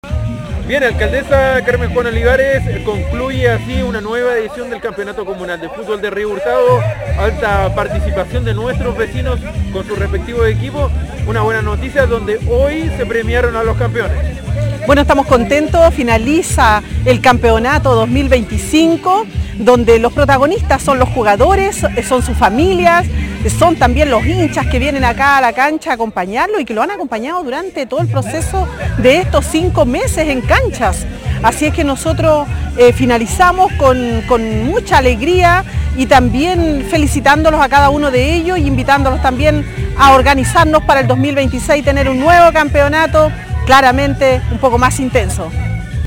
Mientras que la alcaldesa Carmen Juana Olivares valoró la alta participación de los vecinos y vecinas en esta actividad deportiva.